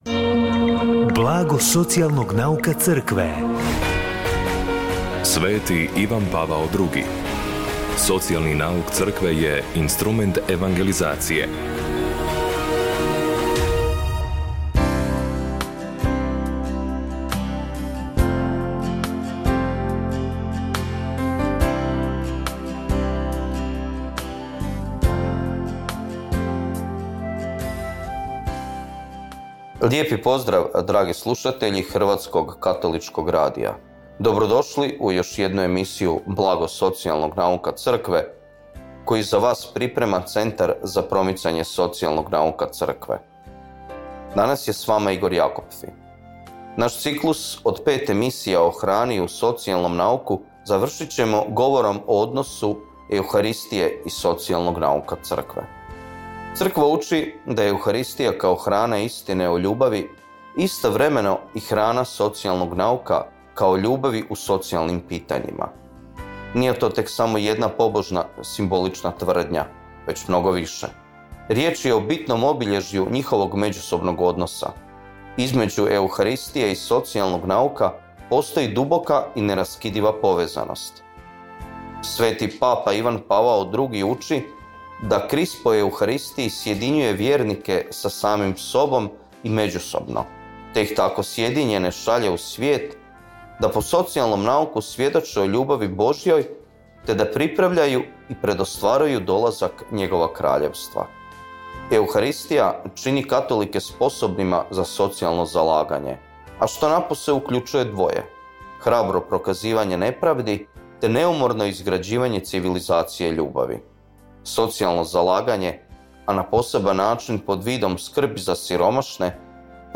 Emisiju na valovima HKR-a „Blago socijalnog nauka Crkve“ subotom u 16:30 emitiramo u suradnji s Centrom za promicanje socijalnog nauka Crkve Hrvatske biskupske konferencije.